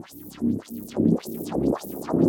Index of /musicradar/rhythmic-inspiration-samples/105bpm
RI_ArpegiFex_105-01.wav